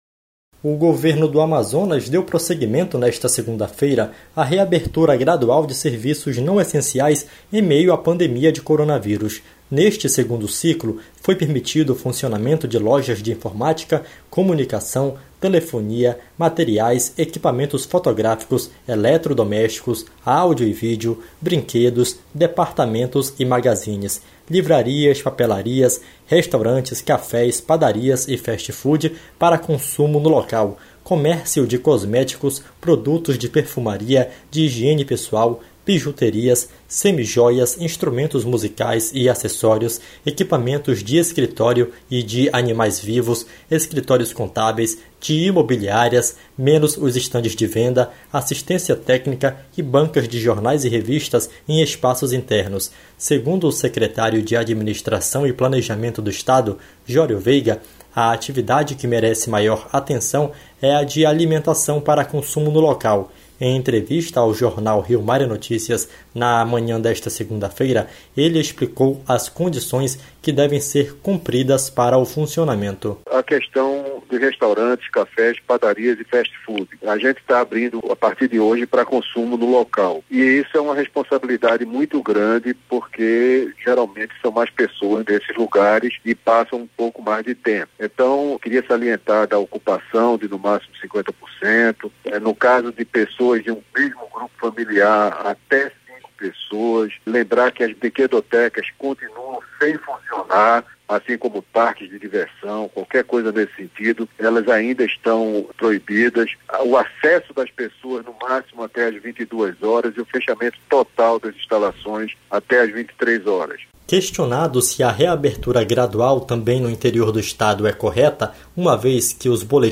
Em entrevista ao Jornal Rio Mar em Notícias, na manhã desta segunda-feira, ele explicou as condições que devem ser cumpridas para o funcionamento.